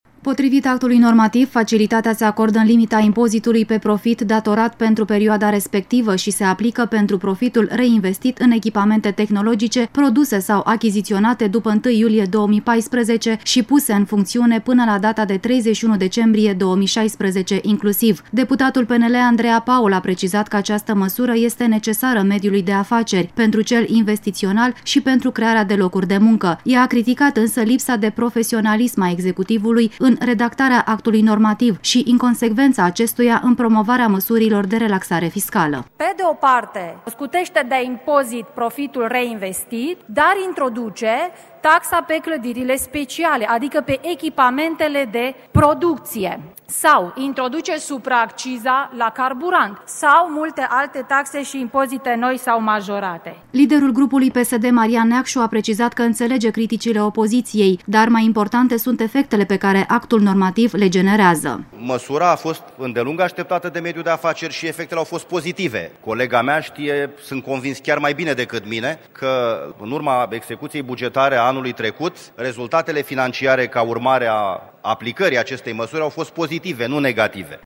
Declaraţia deputatului PSD, Marian Neacşu, încheie relatarea